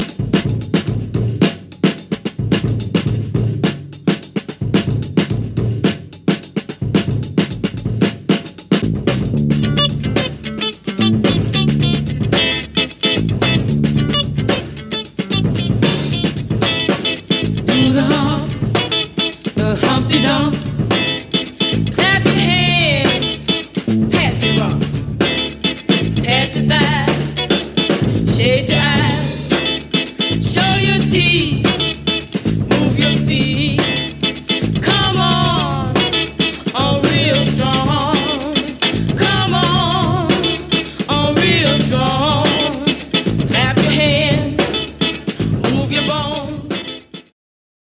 Extended break versions.
extended Breaks Special edition